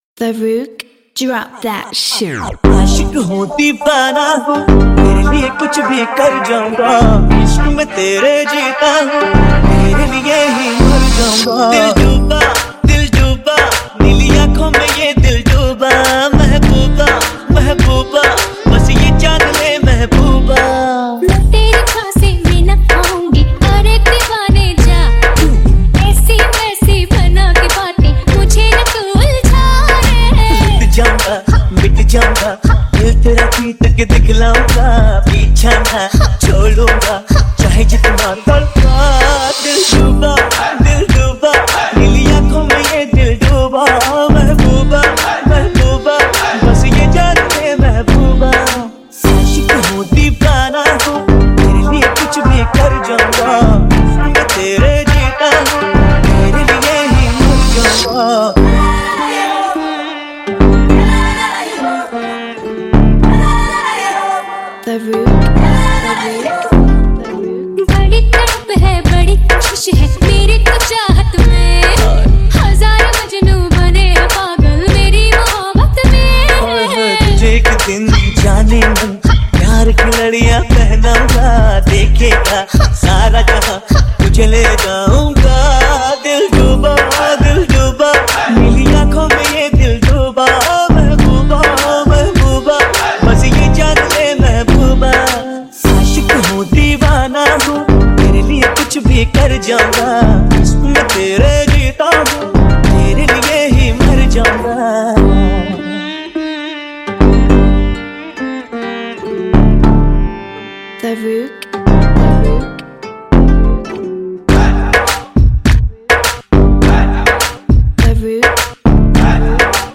Category: Dj Song download